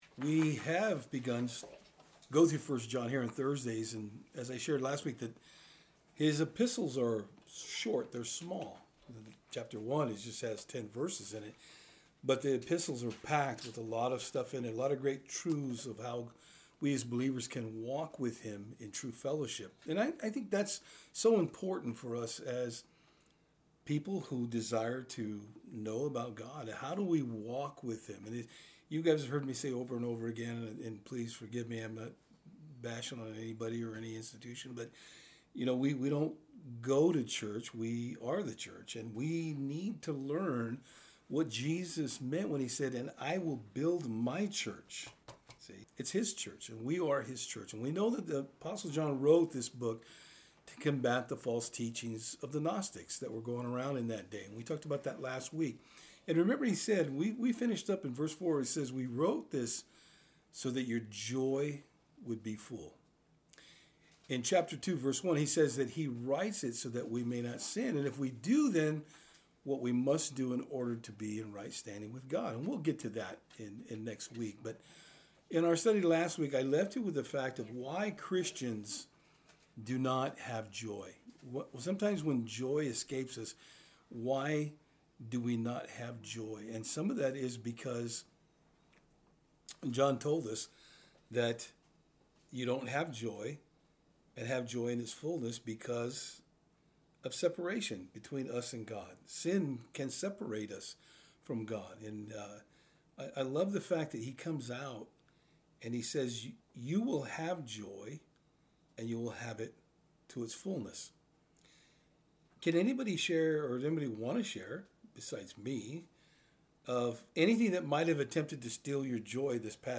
Service Type: Thursday Eveing Studies